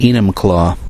Enumclaw (/ˈnəmklɔː/
EE-nəm-klaw) is a city in King County, Washington, United States.
En-us-Enumclaw.ogg.mp3